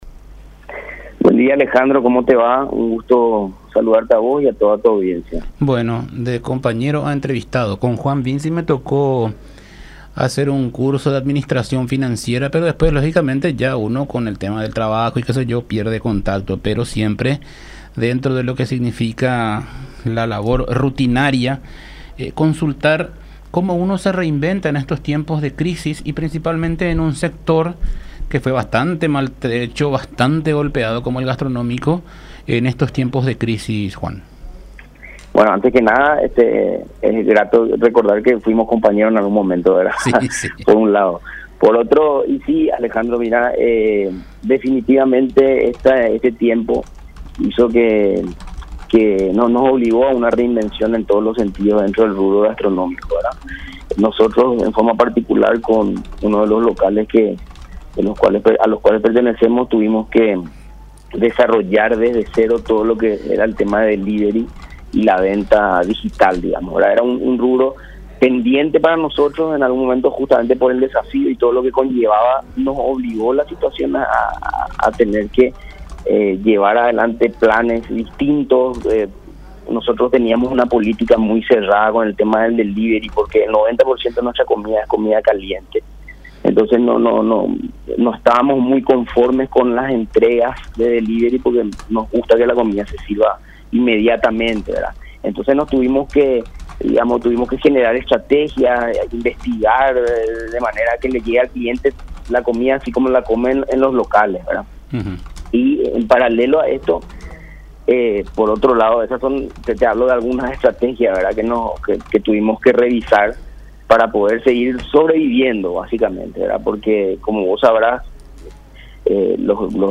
en diálogo con La Unión R800 AM